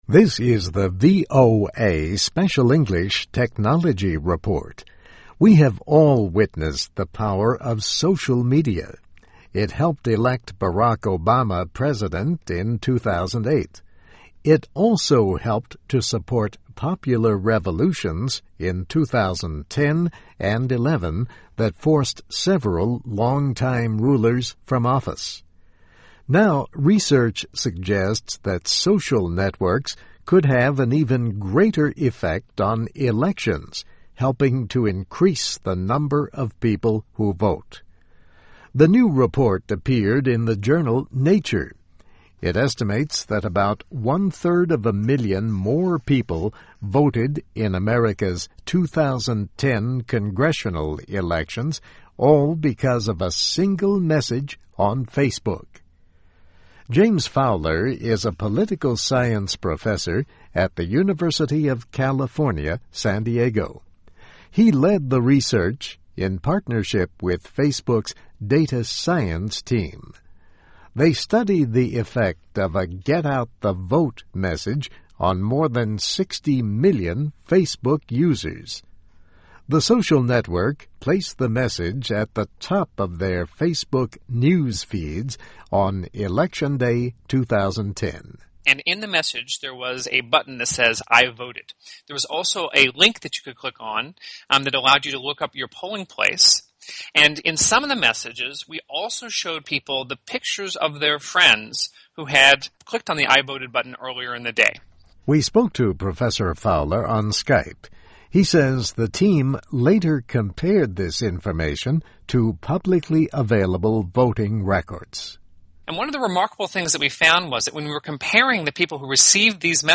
Can Social Media Improve Voter Turnout?|VOA慢速英语